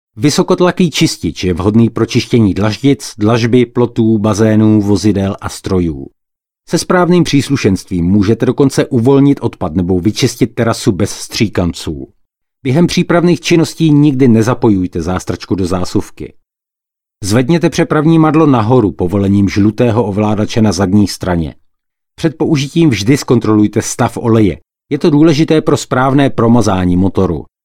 Native speaker Male 30-50 lat
Native Czech voice with a neutral timbre.
Nagranie lektorskie